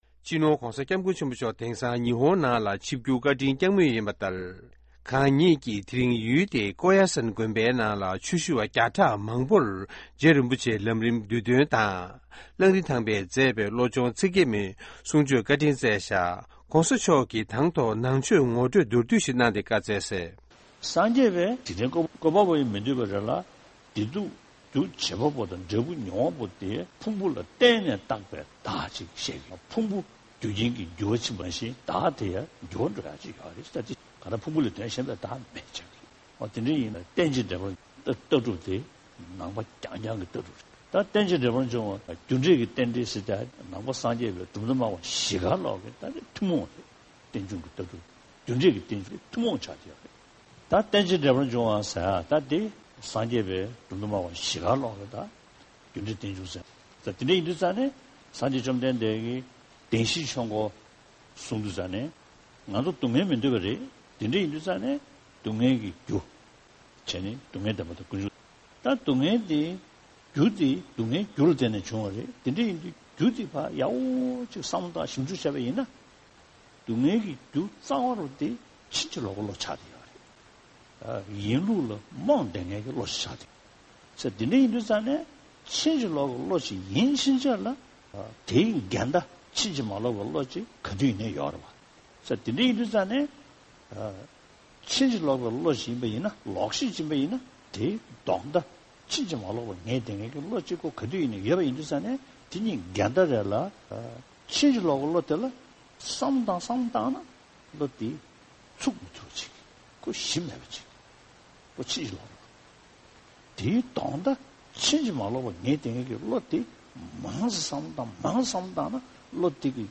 The teachings were received by a large gathering in the mountain town of Koyasan at the Koyasan Monastery, Wakayama, Japan on April 13, 2014.